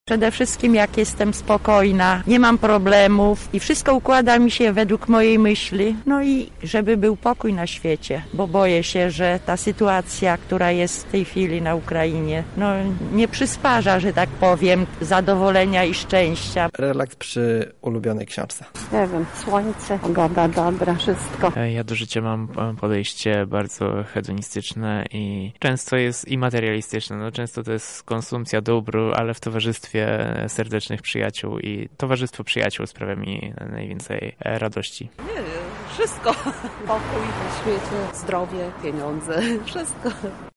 [SONDA] Dzisiejsza kartka w kalendarzu każe nam się uśmiechnąć. Kiedy lublinianie są szczęśliwi?
Z tej okazji nasz reporter zapytał mieszkańców Lublina, co powoduje, że czują radość z życia.
sonda